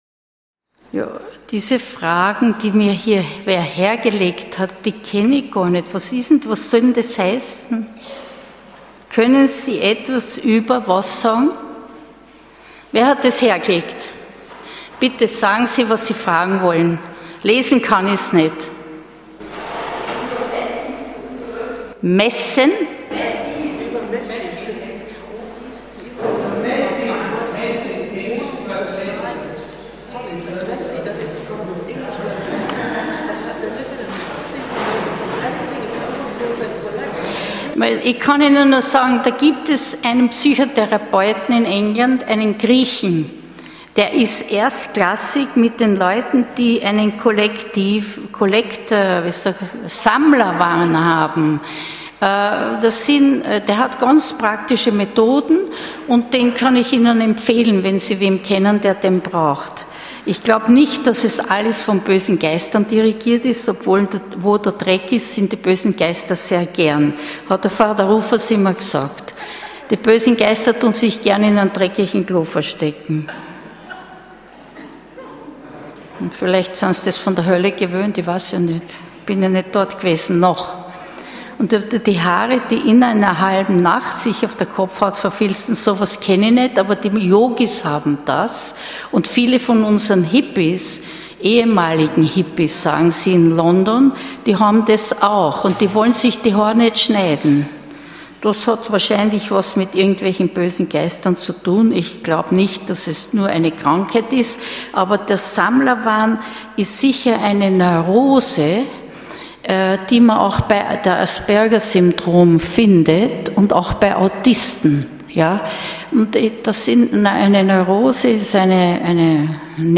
Exerzitien für Innere Heilung in Wien (Marianneum) August 2013